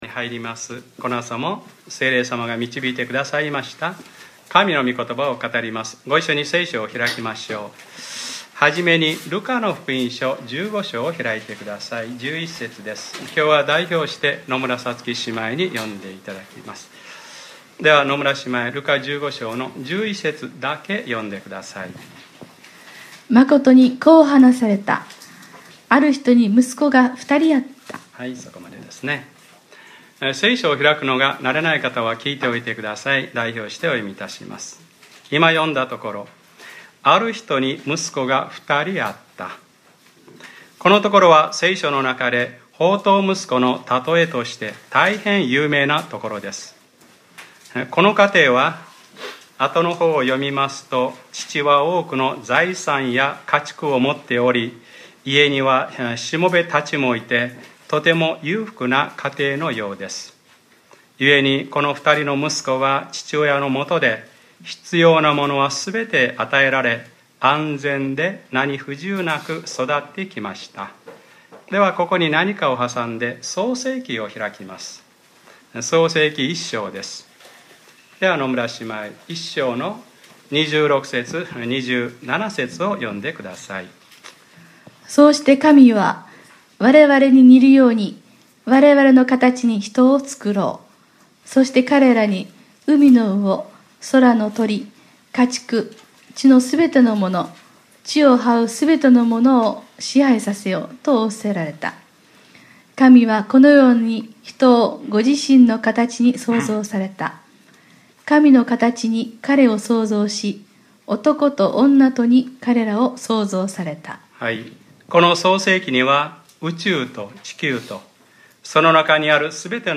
2014年8月3日（日）礼拝説教 『立って、父のところに行って、こう言おう』 | クライストチャーチ久留米教会